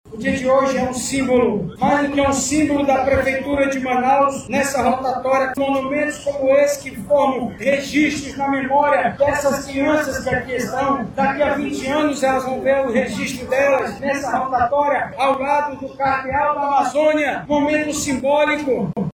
O vice-prefeito de Manaus, Renato Júnior, esteve no evento representando o Poder Executivo, e disse que a estrutura faz parte de um conjunto de obras, que tem o objetivo de criar e resguardar as memórias da cidade.